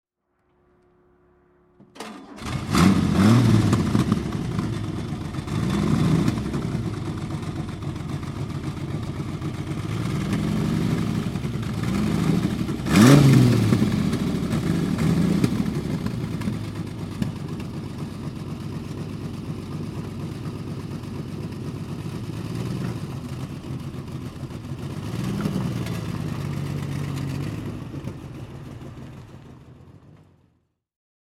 A.S.A. AFF Tipo 61 Barchetta (1963) - Starten und Leerlauf